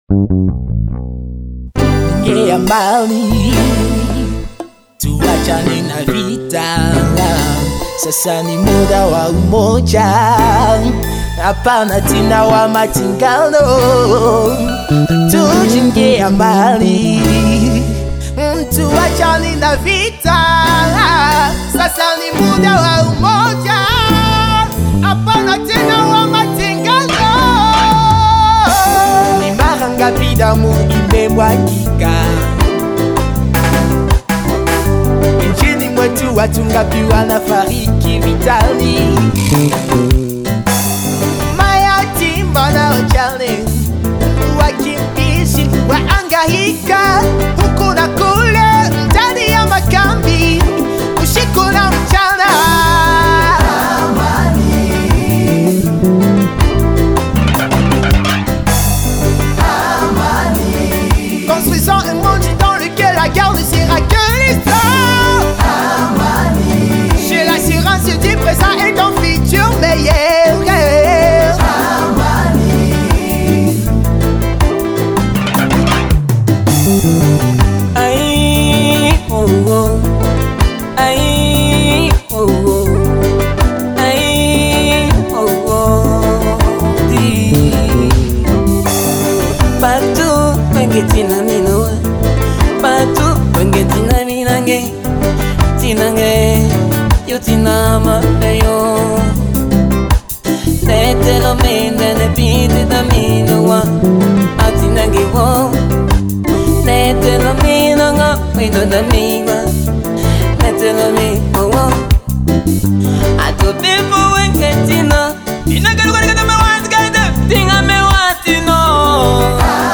Inspired by something from the awesome team at CAFOD, one group of young people created their own poem of what they think peace looks like, whilst another group worked together in a local music studio to record their song.